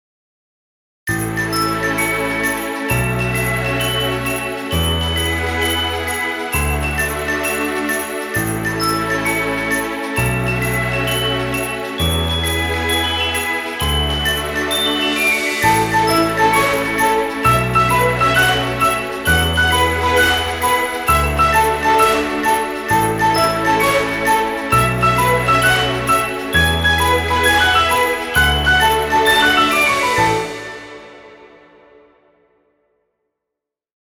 Royalty Free Music.